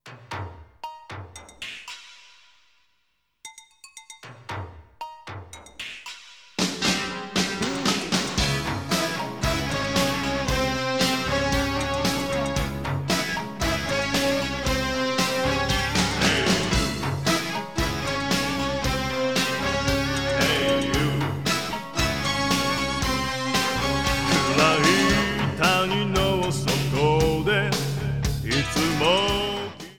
Trimmed, added fadeout